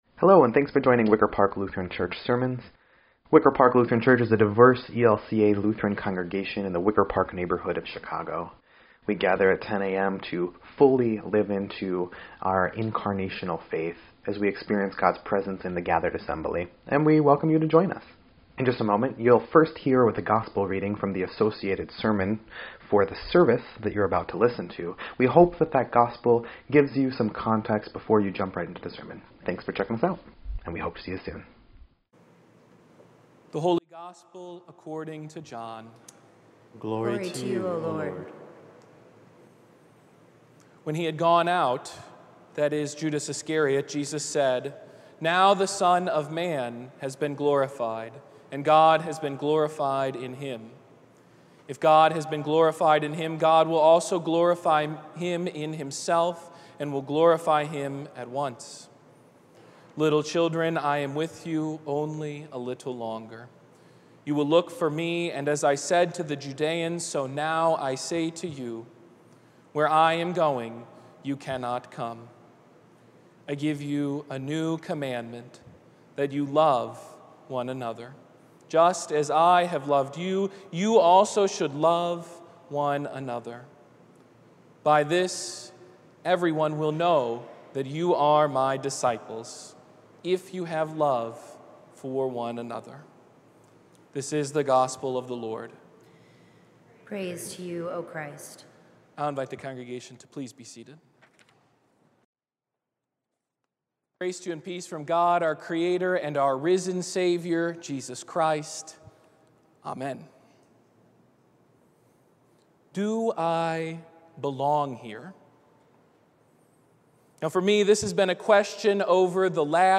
5.15.22-Sermon_EDIT.mp3